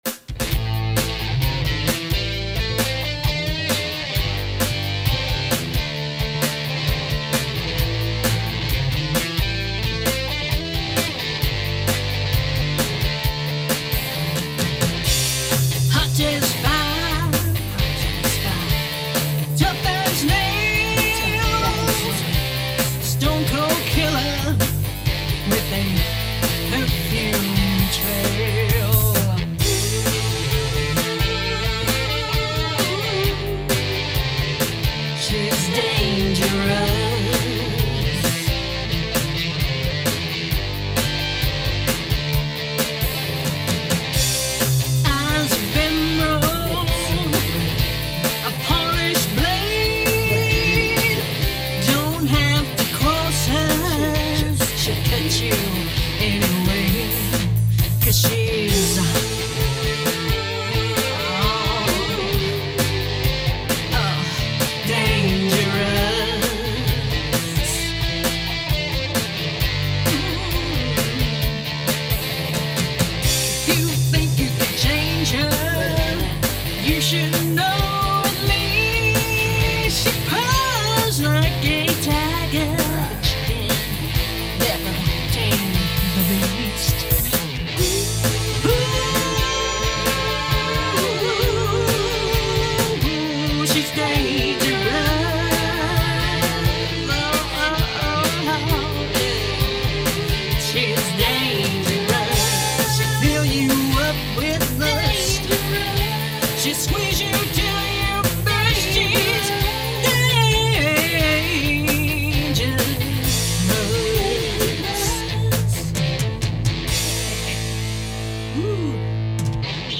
short instrumental track
Killer lycrics, rocking tune.
Awesome vox and rocking guitars!!
Killer Macca bass, rocking rhythm and lead licks!
Beautiful vox with perfect backing harmonies.
Cool - I love the guitar sounds - and the the vox comes along and makes it more complete.